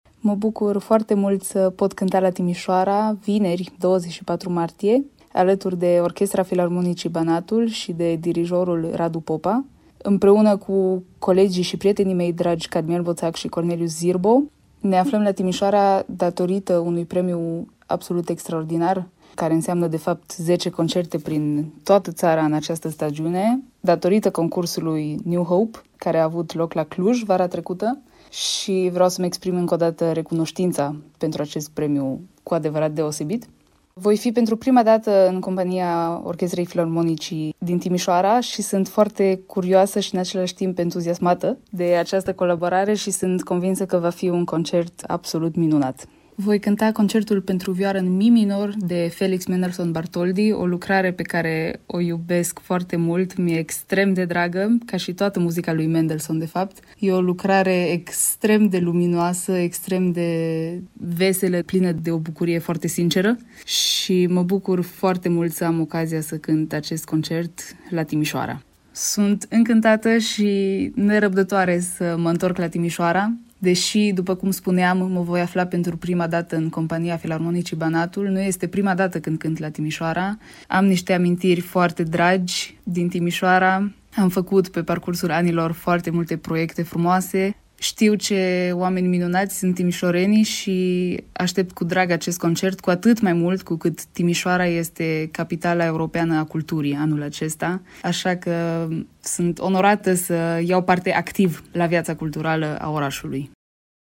interviu Radio Timișoara